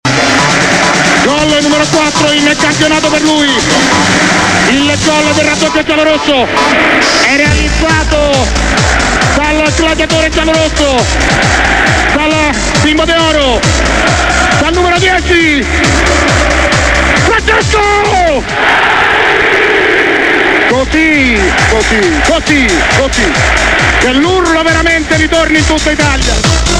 annuncio del goal (315 KB)!
totti_romalazio_annuncio.mp3